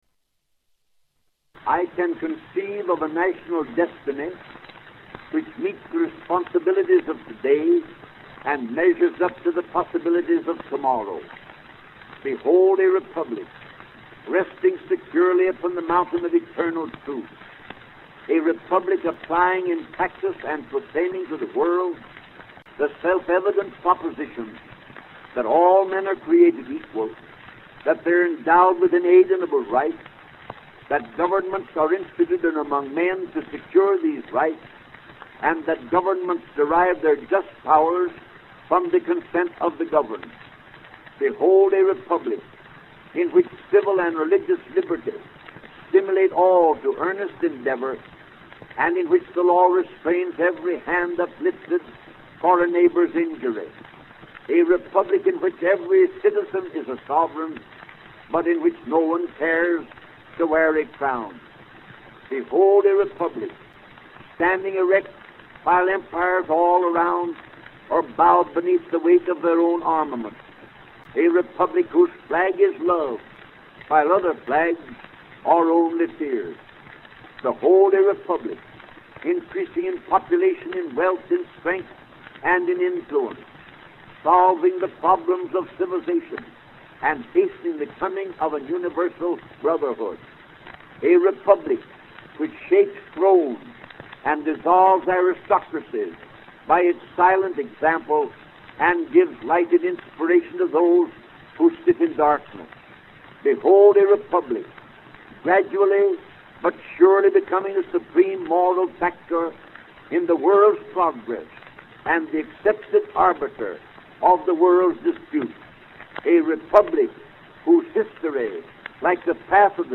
Audio mp3 Excerpt Studio Reading of Address